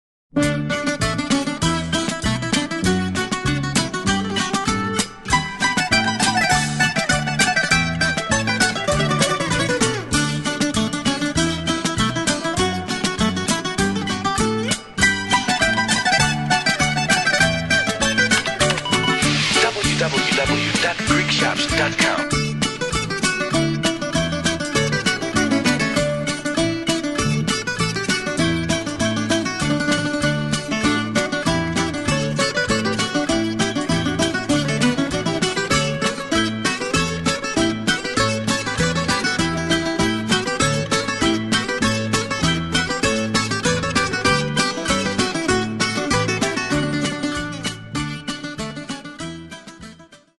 14 great instrumentals from a great composer
Bouzouki soloist